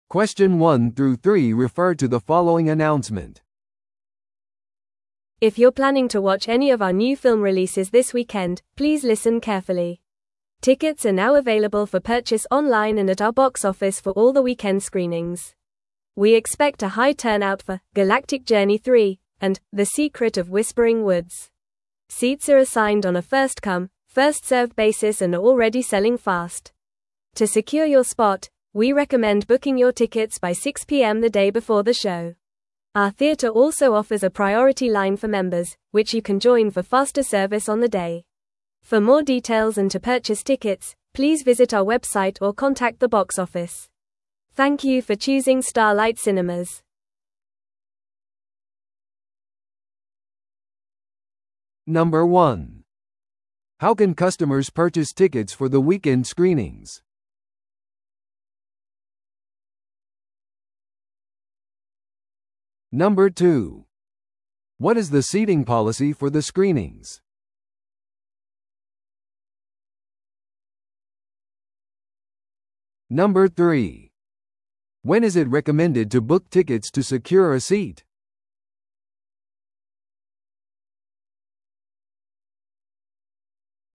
TOEICⓇ対策 Part 4｜新作映画のチケット案内 – 音声付き No.113